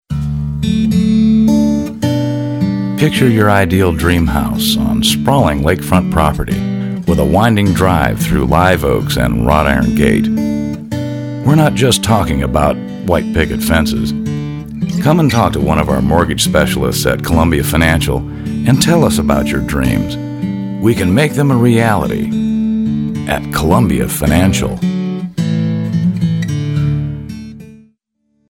Baritone Voice; Voice Age 40-50; Documentaries, Corporate Narratives, Soft Sell Advertising.
Sprechprobe: Werbung (Muttersprache):